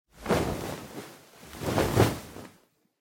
ukutivanie.ogg